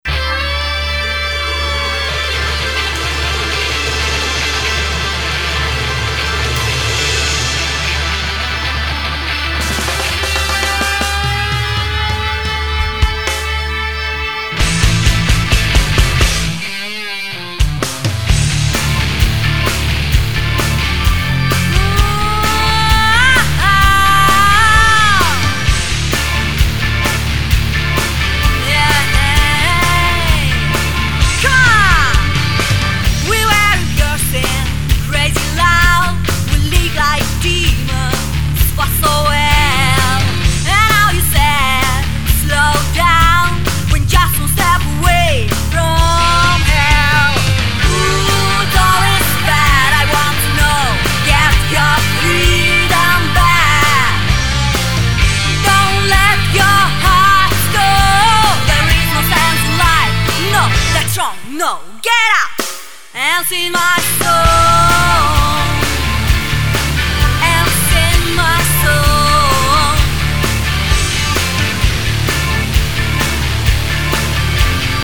(Живая поканальная запись)